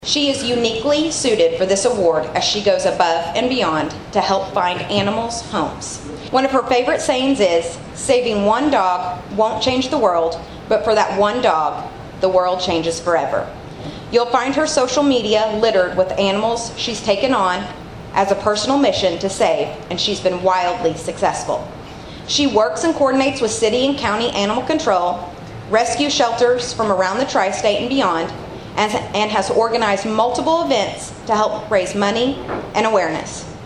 Citizen of the Year, an award handed out at the Carmi Chamber of Commerce annual dinner for 65 years has been won by folks from a variety of walks of life with a variety of areas of expertise.